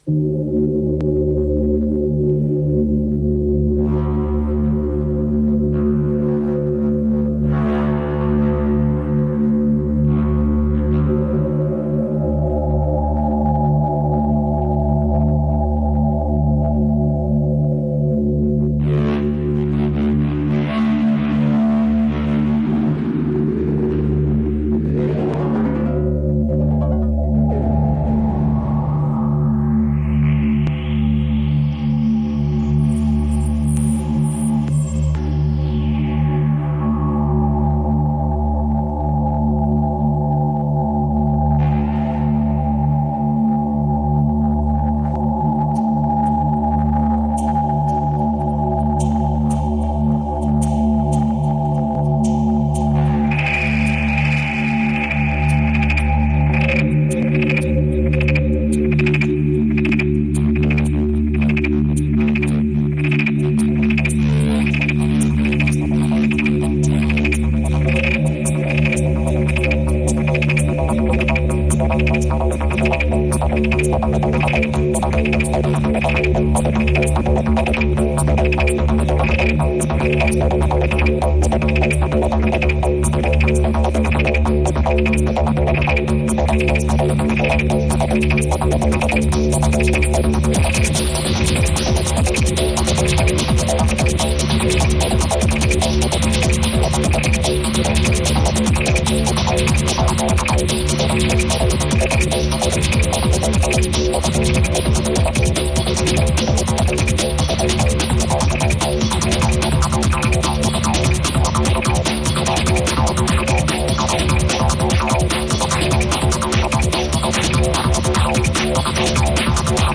venue Flemington Racecourse